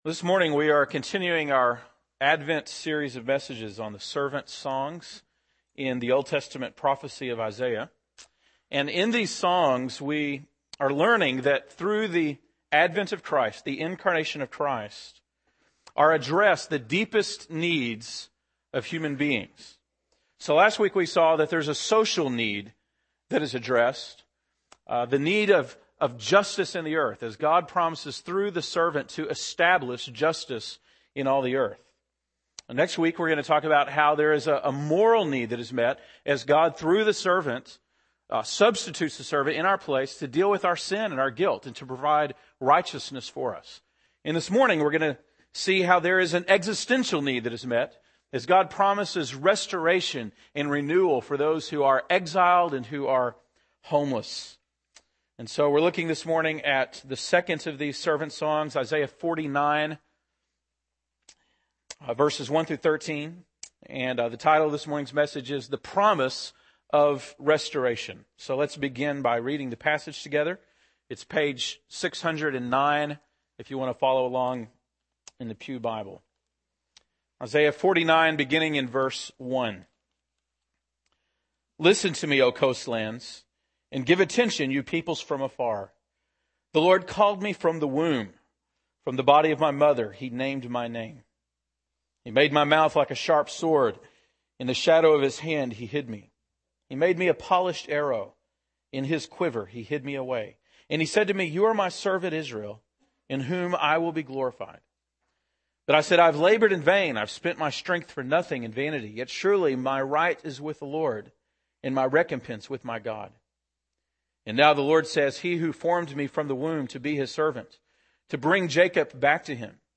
December 13, 2009 (Sunday Morning)